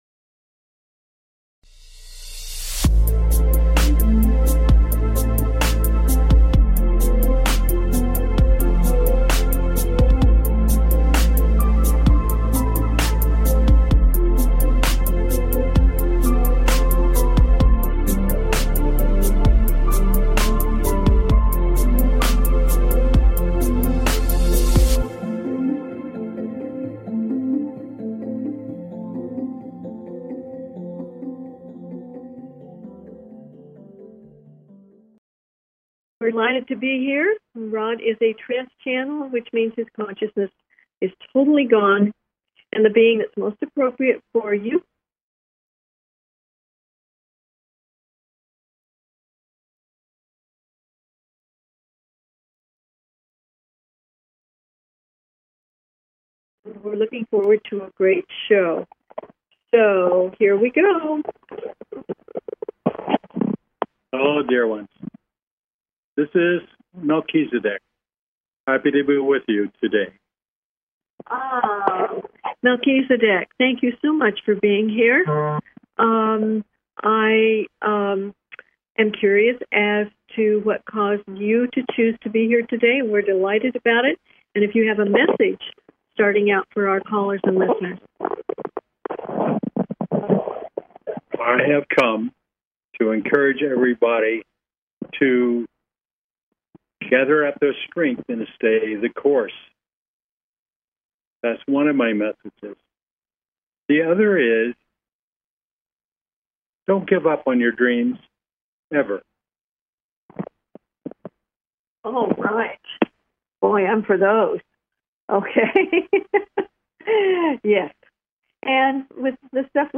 Talk Show Episode, Audio Podcast, The Allow Love Show and with Melchizedek on , show guests , about Melchizedek, categorized as Paranormal,Ghosts,Philosophy,Politics & Government,Spiritual,Access Consciousness,Medium & Channeling